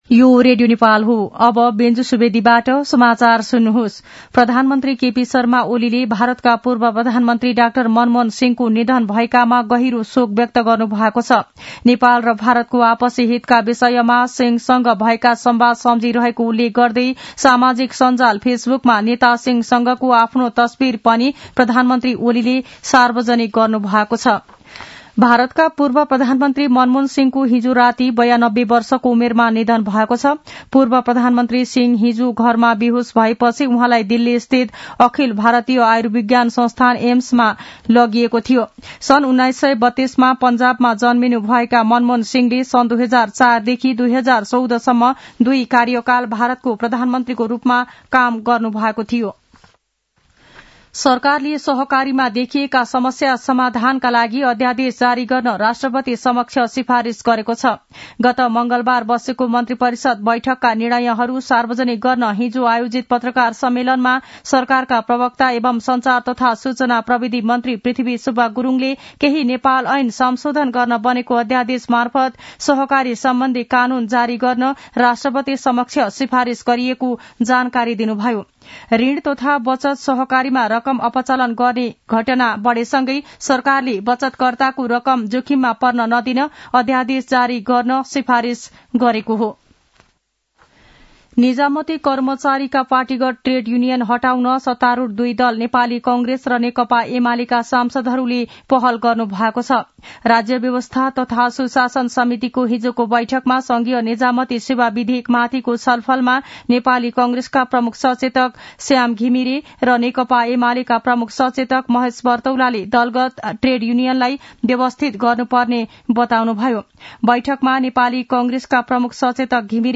मध्यान्ह १२ बजेको नेपाली समाचार : १३ पुष , २०८१
12-am-nepali-news-1-20.mp3